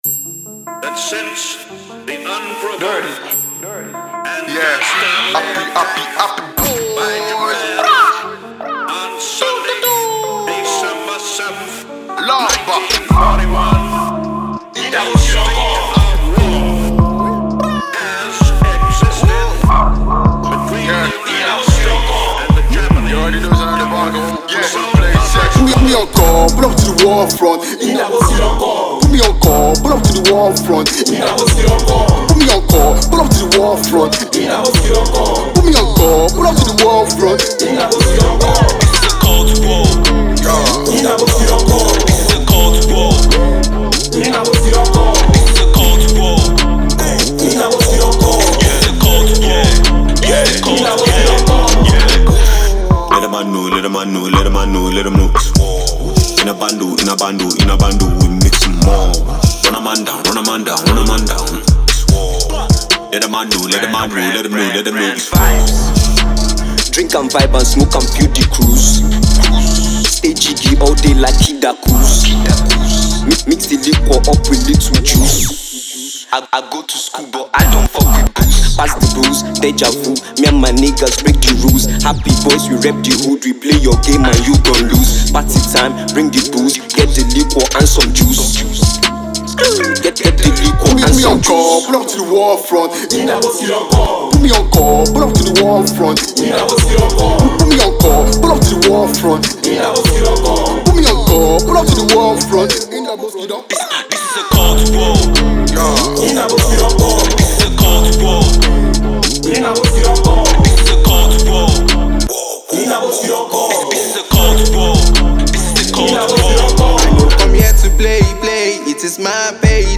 drill vibe